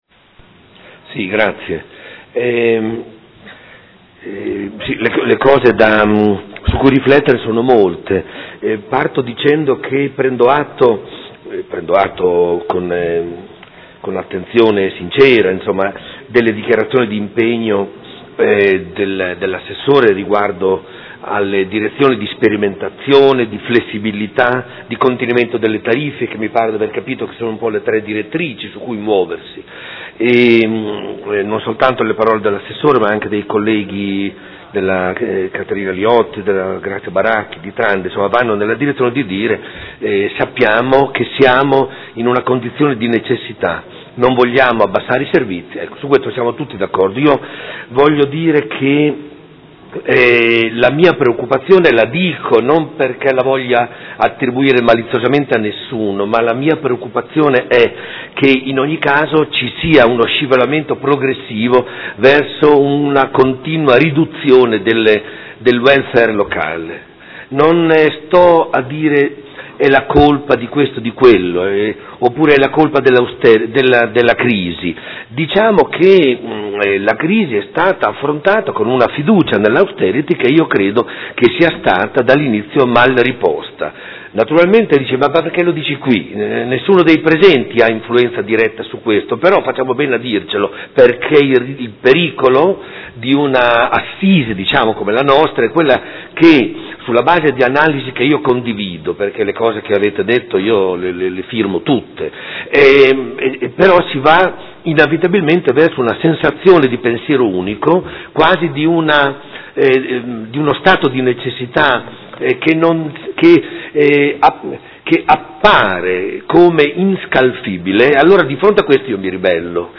Domenico Campana — Sito Audio Consiglio Comunale
Dibattito. Interrogazione del Consigliere Rocco (FaS-S.I.) avente per oggetto: Bando comunale servizio nidi e Interrogazione del Gruppo Consiliare Per Me Modena avente per oggetto: Qual è la situazione dei nidi a Modena?